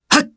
Fluss-Vox_Attack2_jp.wav